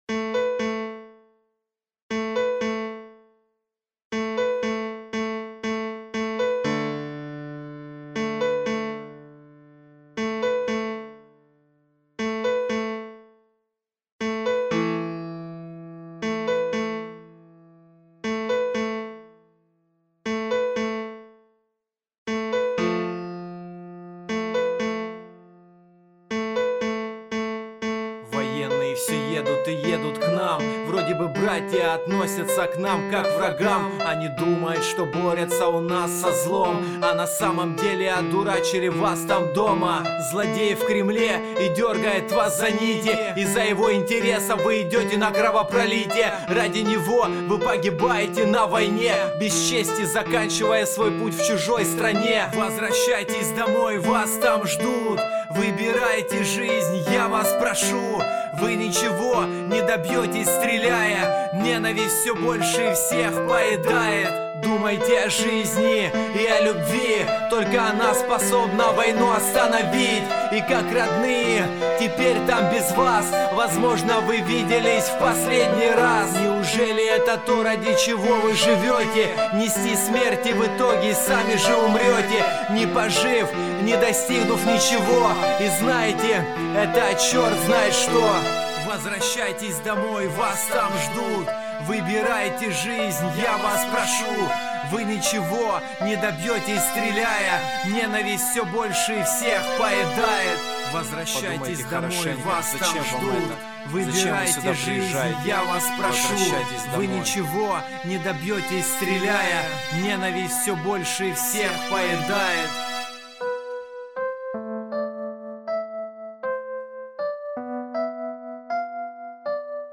Шум после обработки VST плагинами